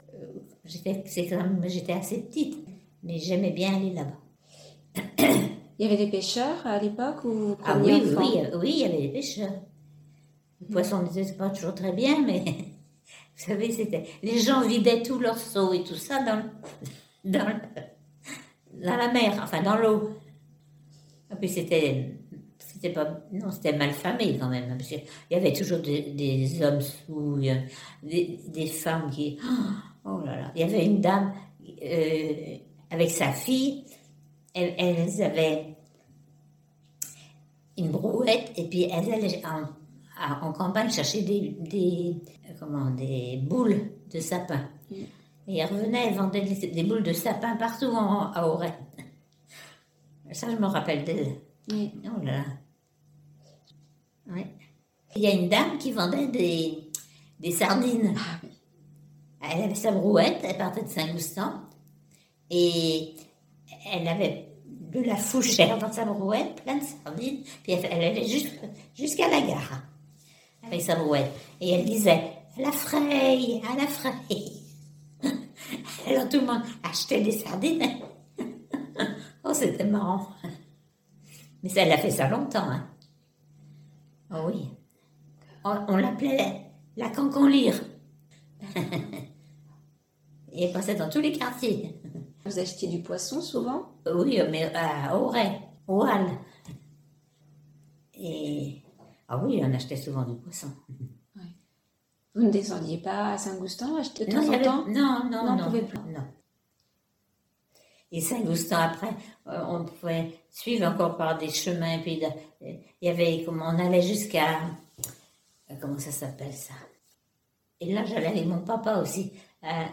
Témoignages audio